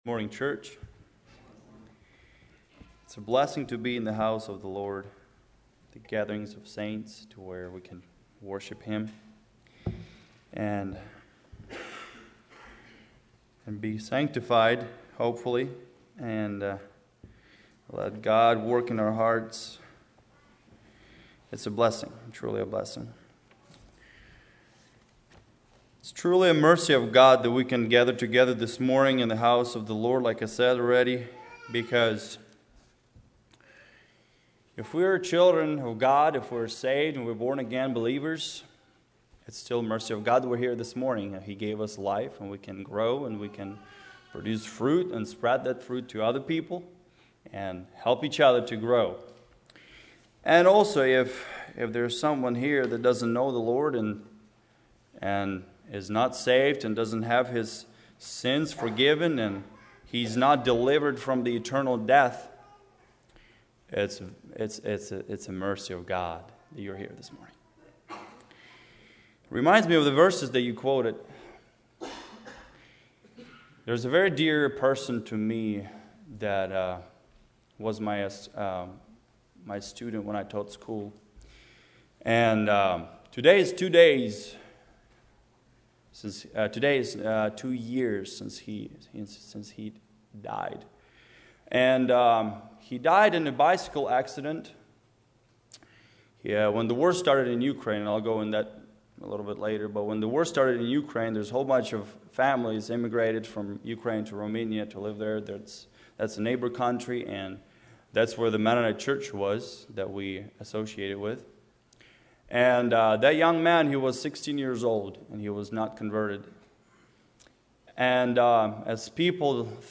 Personal Testimony